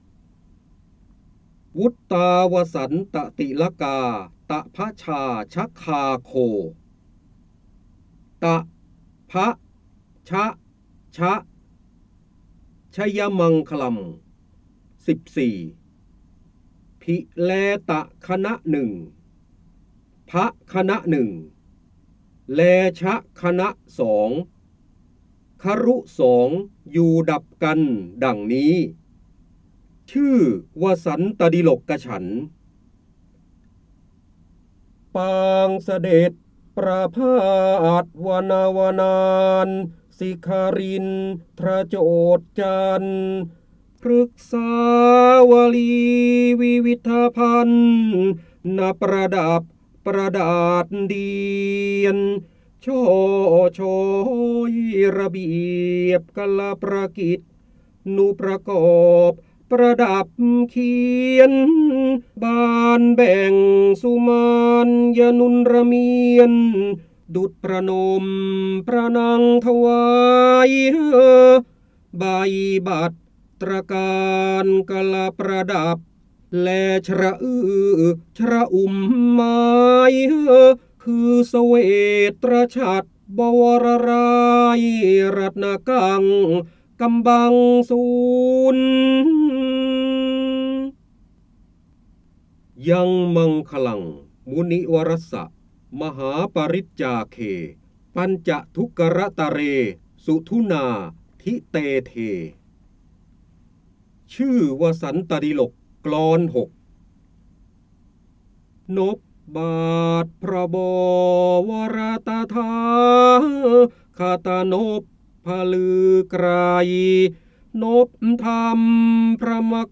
เสียงบรรยายจากหนังสือ จินดามณี (พระโหราธิบดี) วุต์ตาวสัน์ตติลกาตภชาชคาโค
คำสำคัญ : พระเจ้าบรมโกศ, การอ่านออกเสียง, ร้อยกรอง, พระโหราธิบดี, จินดามณี, ร้อยแก้ว